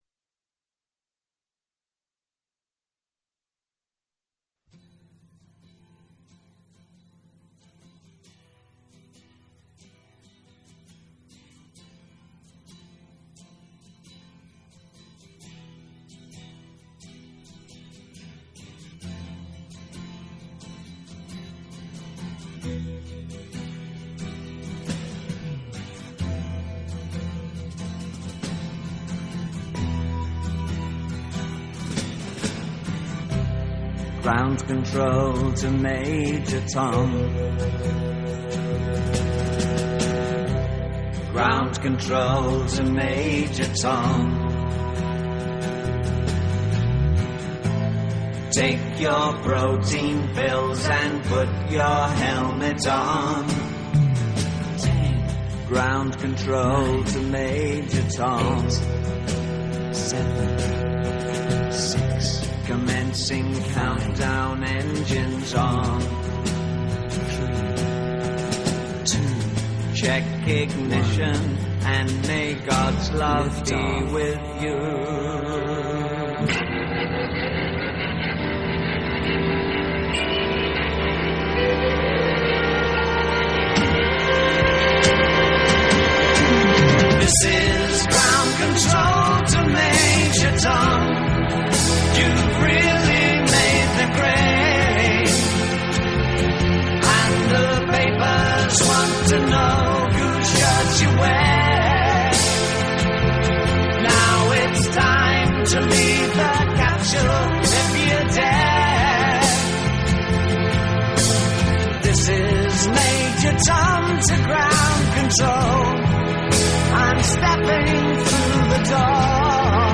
Glam Rock, Live